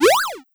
PAJumpSFX.wav